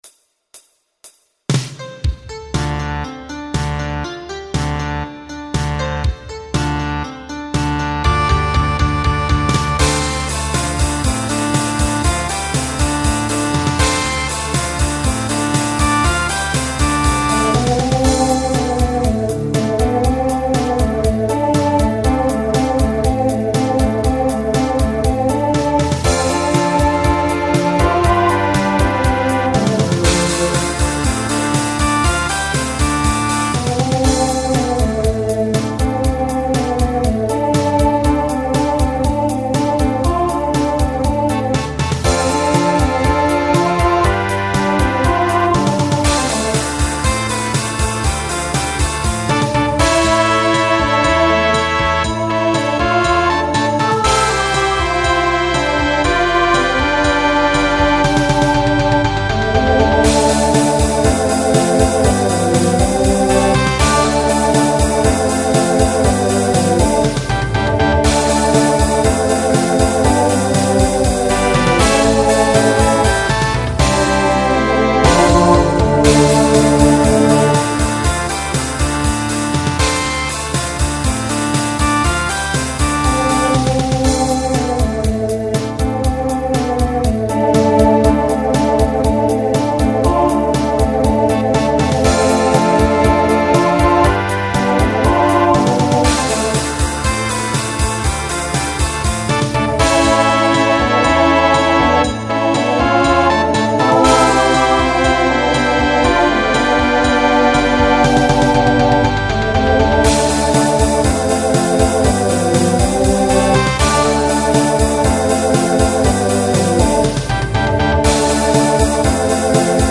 Some SSA
Voicing TTB Instrumental combo Genre Country